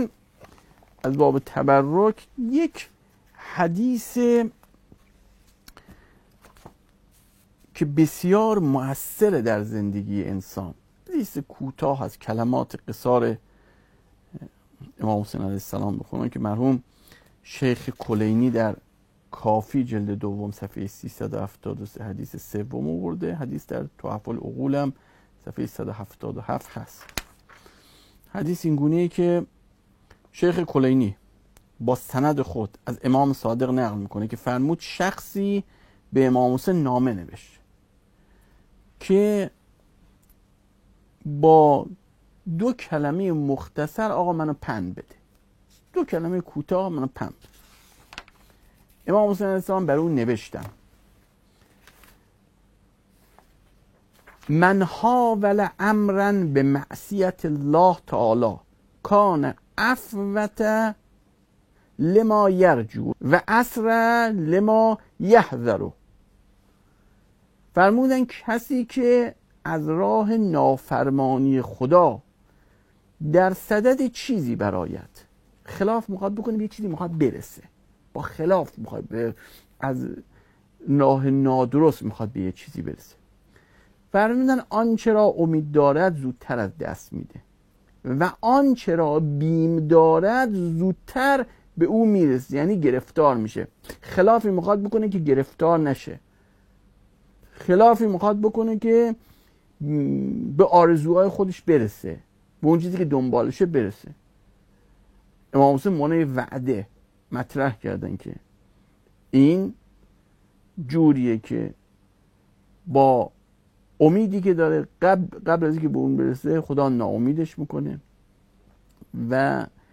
صوت سخنرانی: